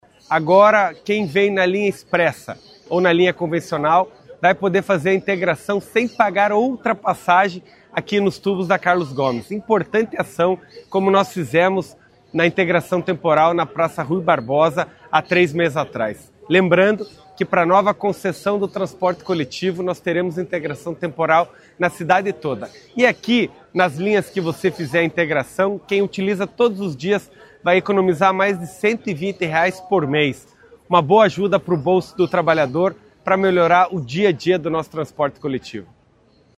O prefeito Eduardo Pimentel lembrou que a Prefeitura também já fez a integração temporal na Praça Rui Barbosa, em agosto.